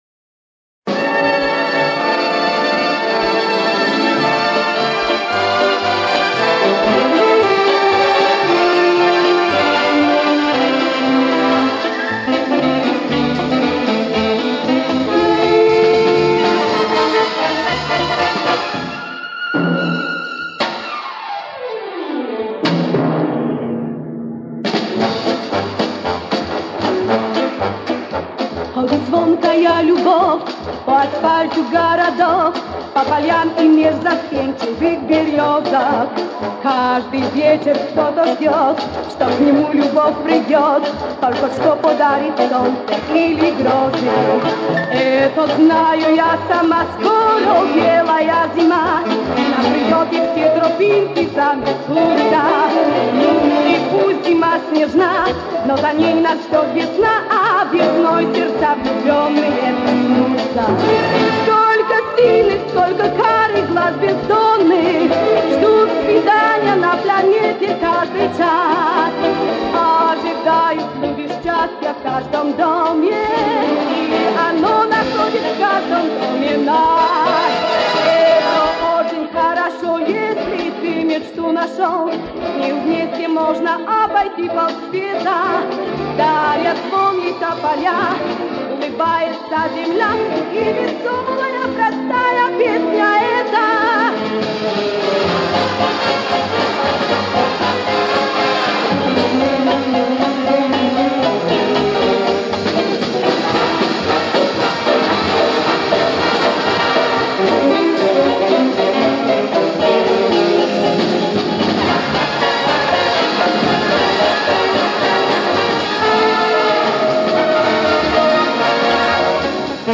Записал 4 песни с пластиночки.